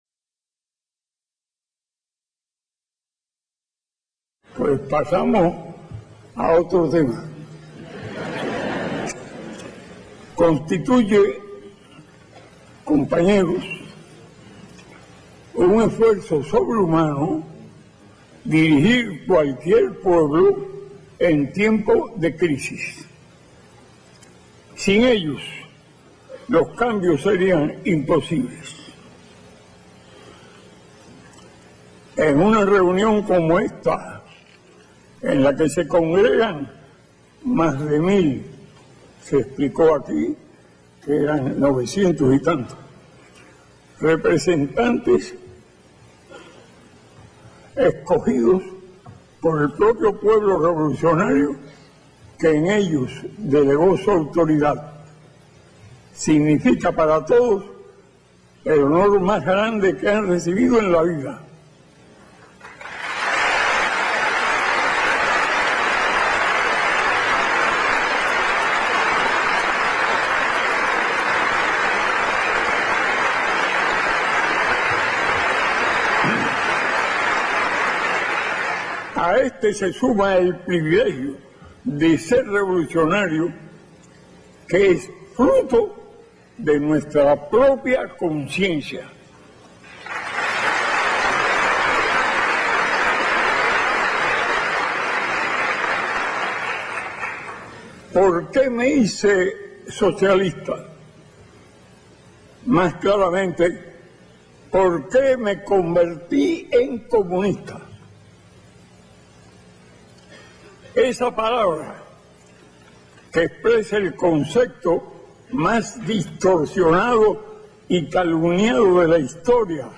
Discursos
palabras-de-fidel-castro-en-el-vii-congreso-del-partido.mp3